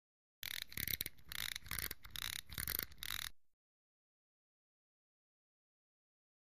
Misc. Sports Elements; Four Winds Of The Stopwatch.